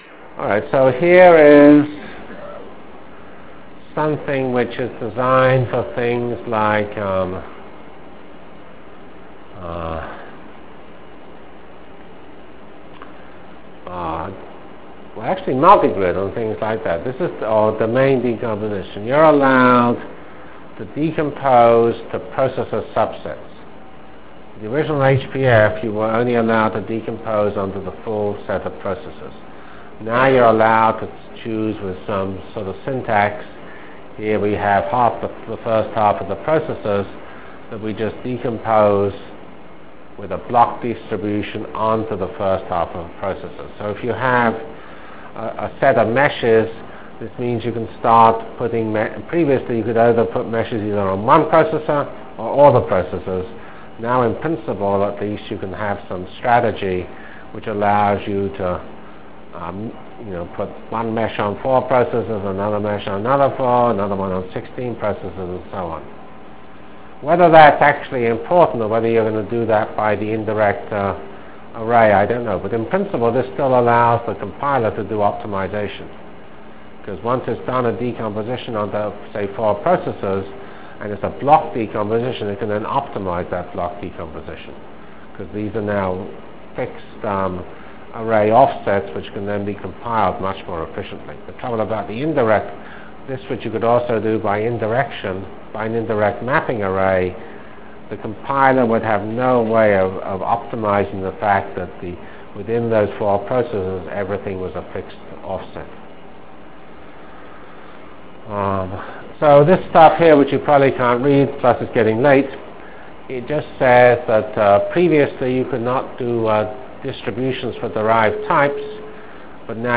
From CPS615-End of Numerical Integration and Very End of HPF Delivered Lectures of CPS615 Basic Simulation Track for Computational Science -- 24 October 96.